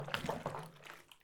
water-swuihllosh
Category 🌿 Nature
bath bubble burp click drain drip drop droplet sound effect free sound royalty free Nature